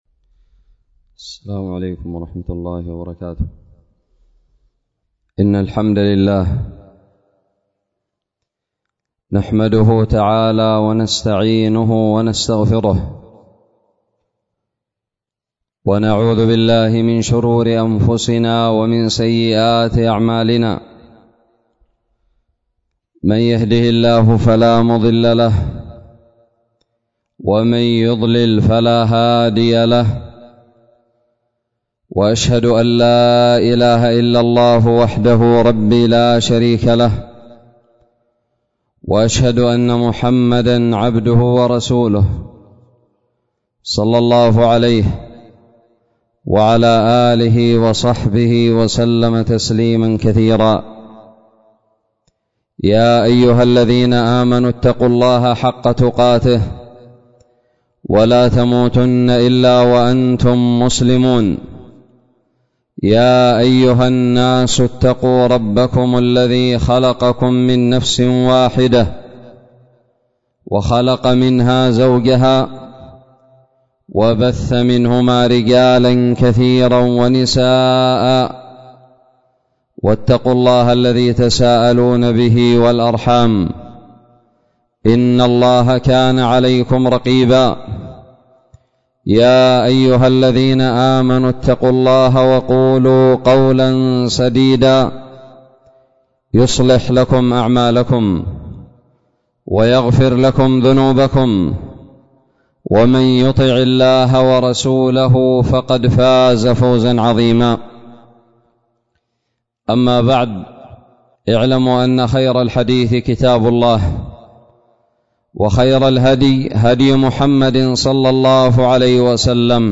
خطب الجمعة
ألقيت بدار الحديث السلفية للعلوم الشرعية بالضالع في 2 شوال 1442هــ